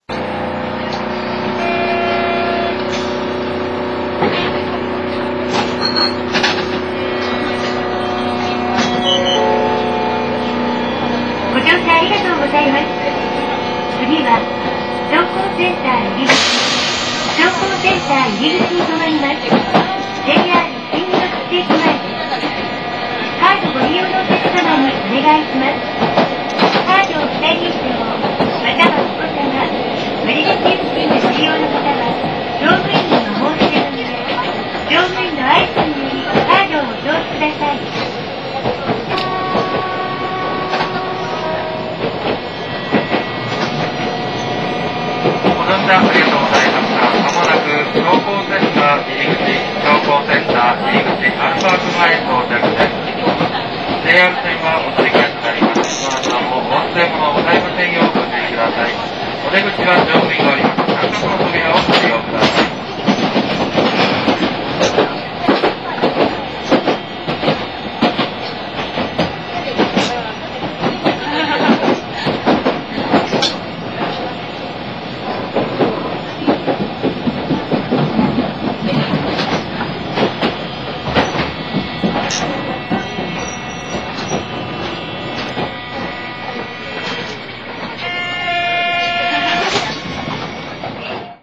■車内で聴ける音■